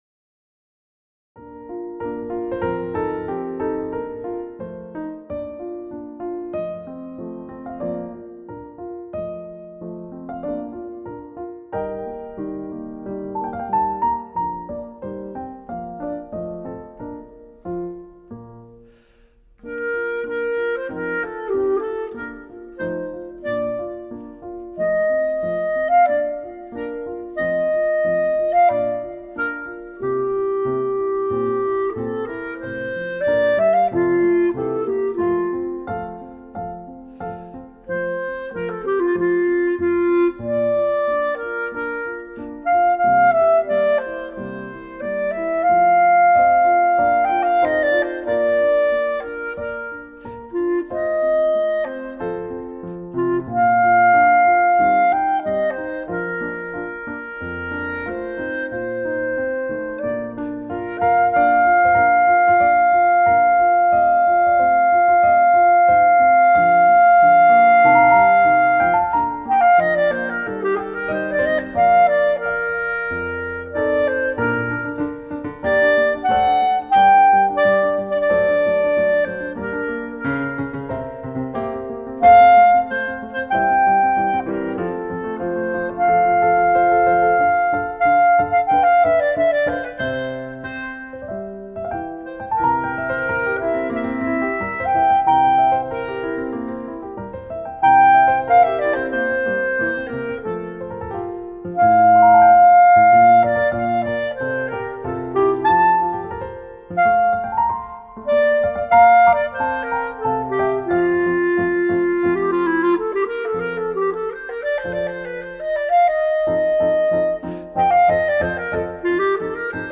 Voicing: Clarinet Solo